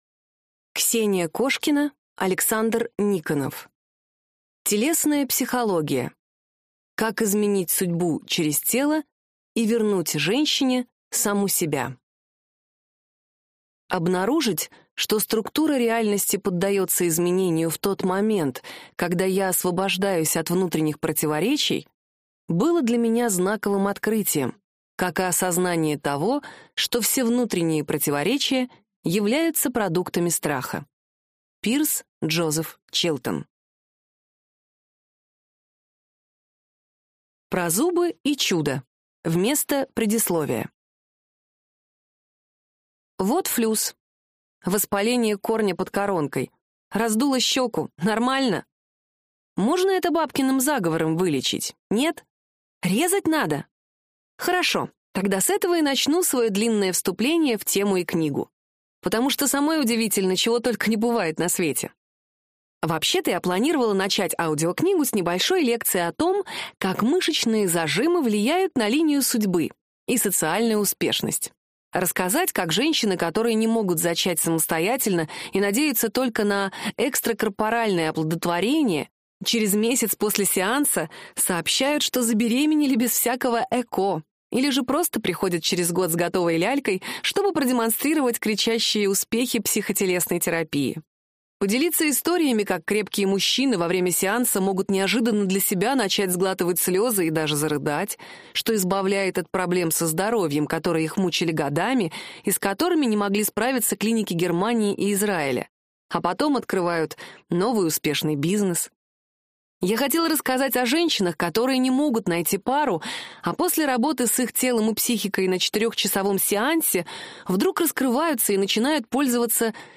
Аудиокнига Телесная психология: как изменить судьбу через тело и вернуть женщине саму себя | Библиотека аудиокниг